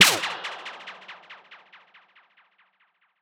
027 drum kit sample 2 - snare Luger 1.wav